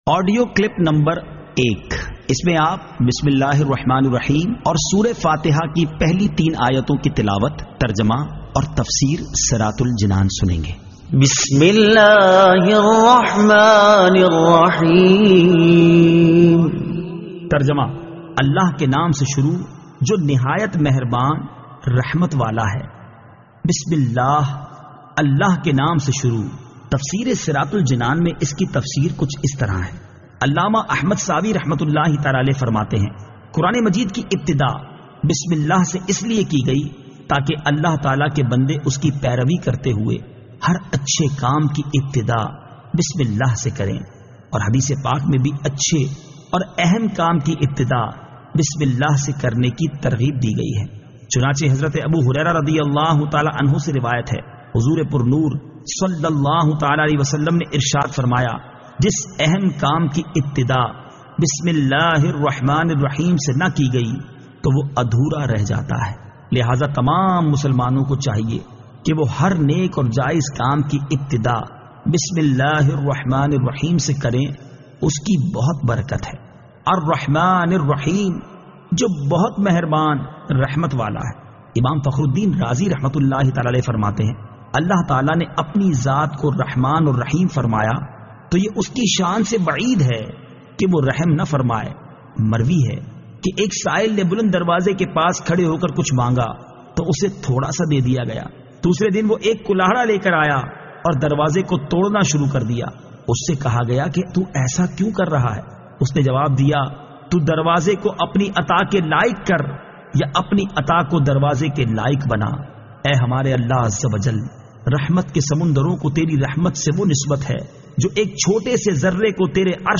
Surah Al-Fatiha Ayat 01 To 03 Tilawat , Tarjama , Tafseer